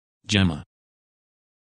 Написание и аудио произношение – Spelling and Audio Pronunciation